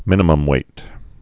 (mĭnə-məm-wāt)